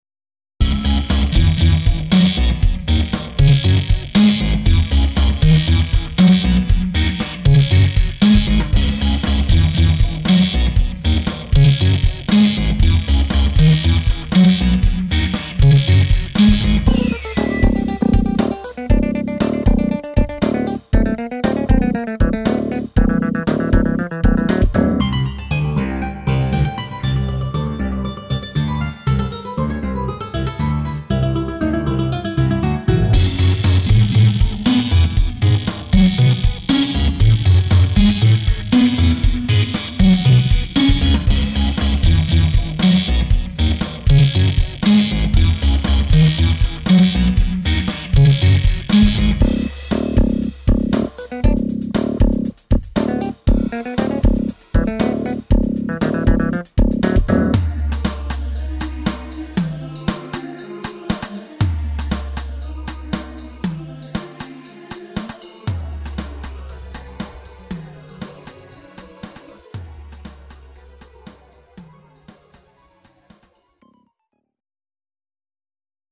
—Synthesizers triggered by guitars
—Guitars played through synths
—Sonics that reverberate from within the earth
—Pulsations to make you move your hips